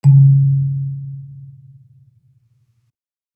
kalimba_bass-C#2-mf.wav